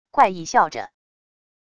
怪异笑着wav音频